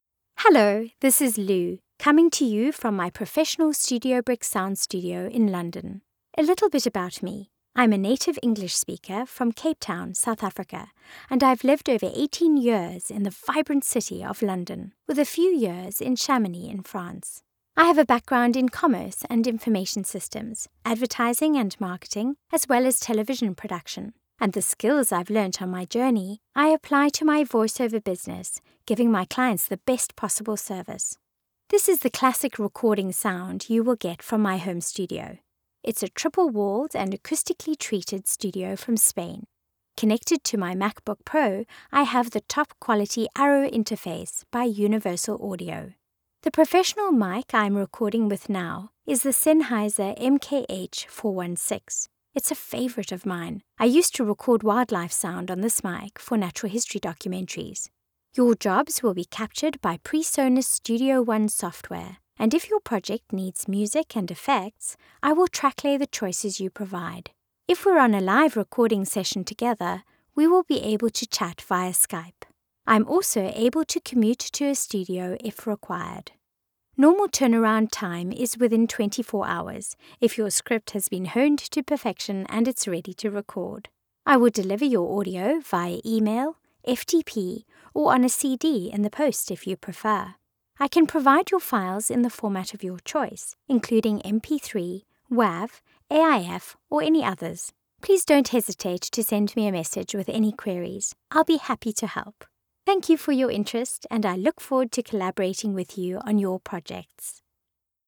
Female
English (South African), English (Neutral - Mid Trans Atlantic)
Yng Adult (18-29), Adult (30-50)
Studio Quality Sample
Raw Home Studio Sample
Natural Speak
From My Home Studio